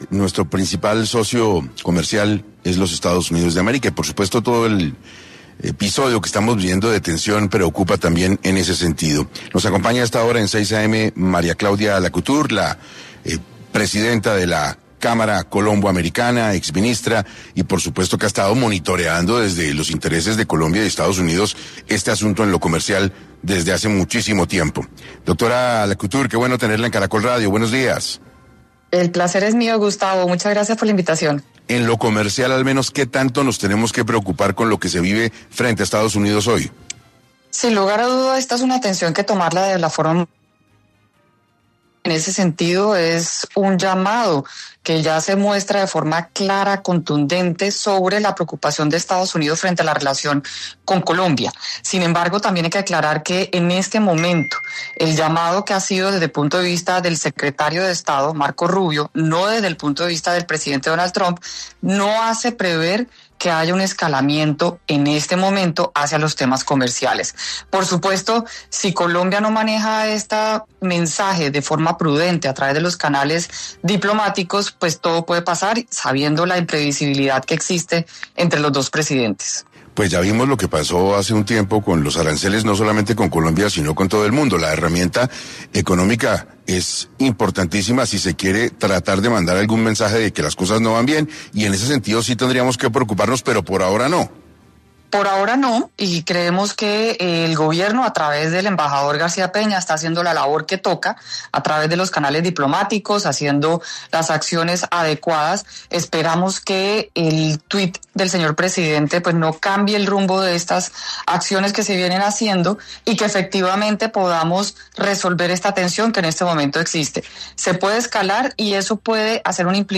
María Claudia Lacouture, exministra de Comercio, estuvo en 6AM para hablar de la crisis bilateral entre Colombia y EE.UU.
En este orden de ideas, Lacouture pasó por los micrófonos de 6AM para profundizar en cómo podría Colombia mediar para evitar una mayor crisis diplomática con Estados Unidos.